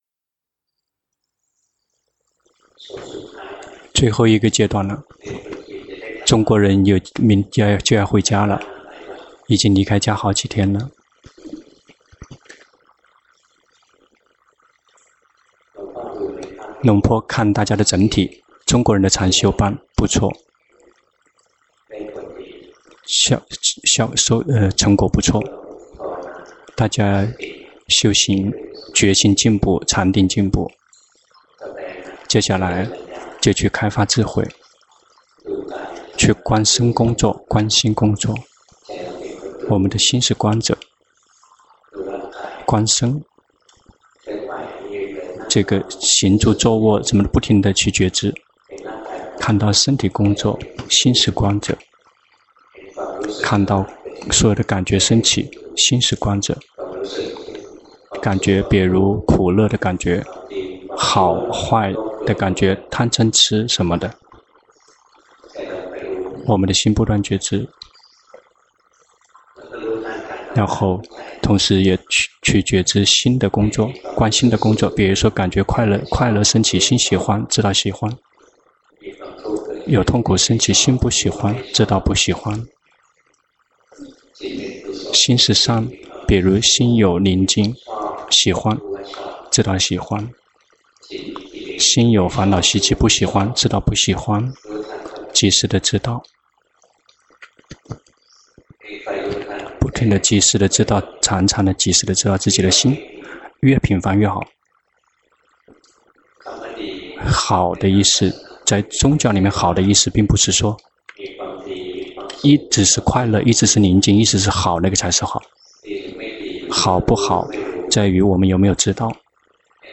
長篇法談｜好或不好，取決於是否知道